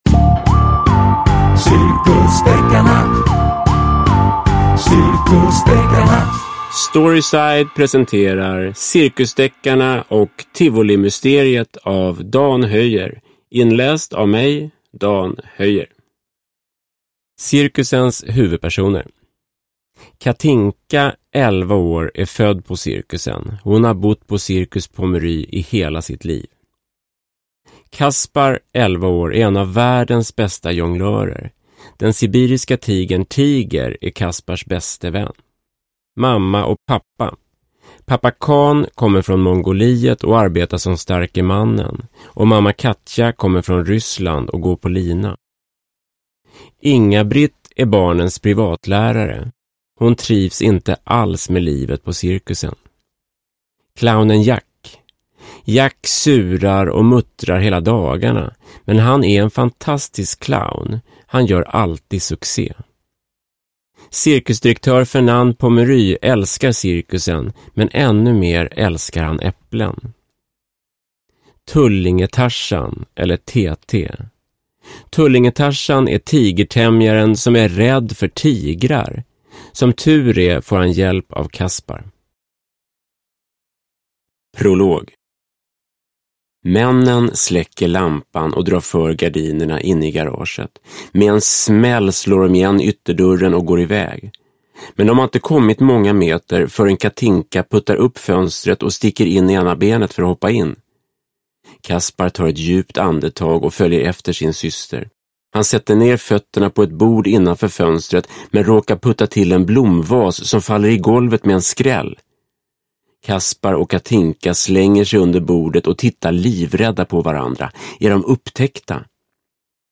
Cirkusdeckarna och tivolimysteriet – Ljudbok – Laddas ner